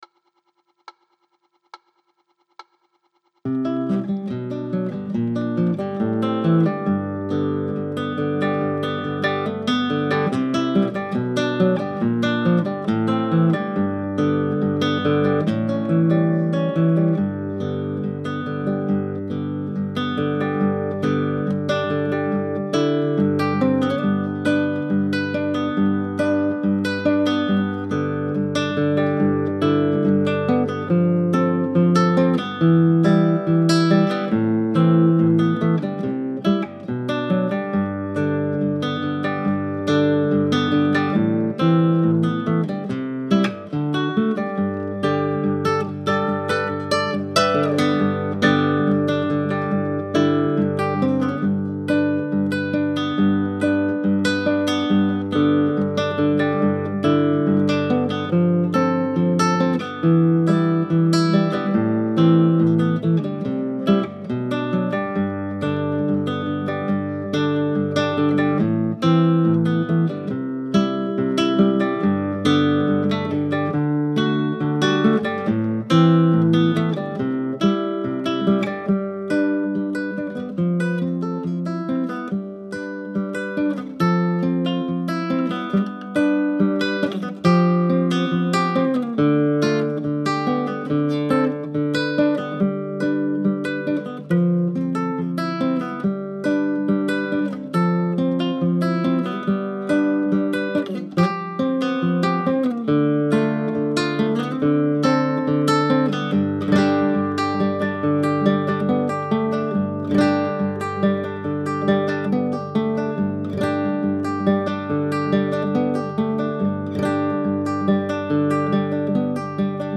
With nylon string and a lot of fluttery tape and 70 bpm